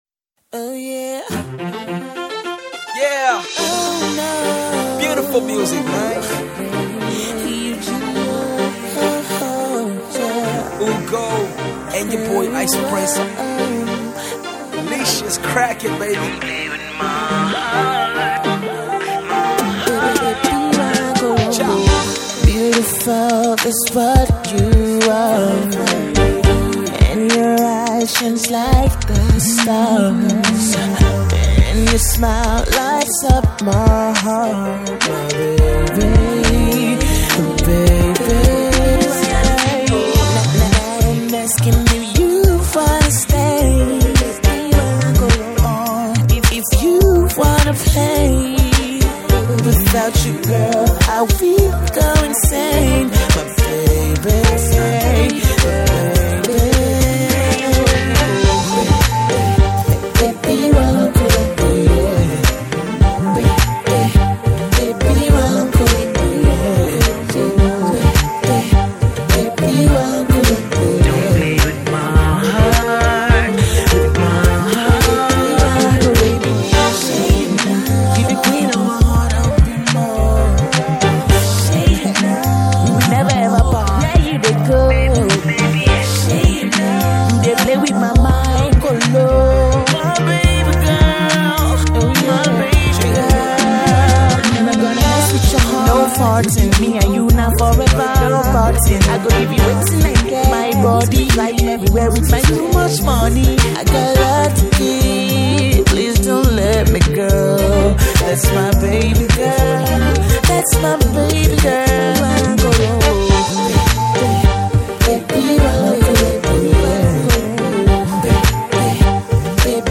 Splendid vocals
soulful and jazzy production, African drums…